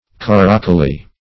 Search Result for " caracoly" : The Collaborative International Dictionary of English v.0.48: Caracoly \Car"a*col`y\, n. An alloy of gold, silver, and copper, of which an inferior quality of jewelry is made.